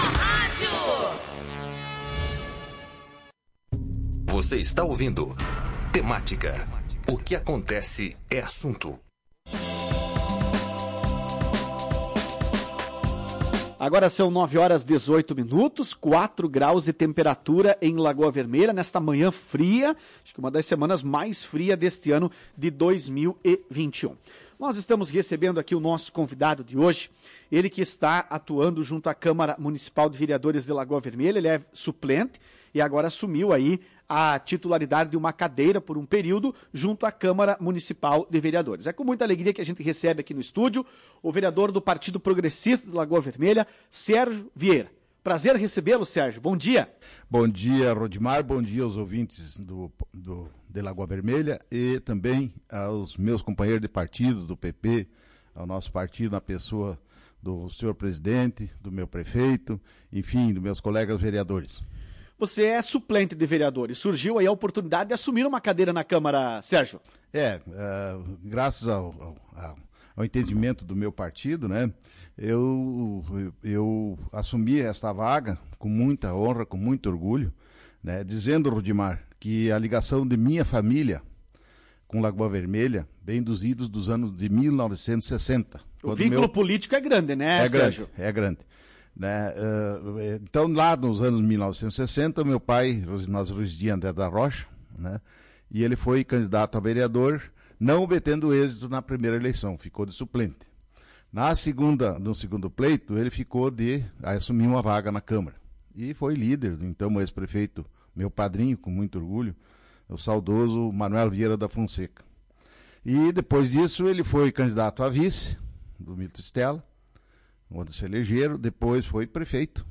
Em entrevista à Tua Rádio Cacique, na manhã desta segunda-feira (19), o vereador Sérgio Vieira destacou seu objetivo de trabalho enquanto vereador.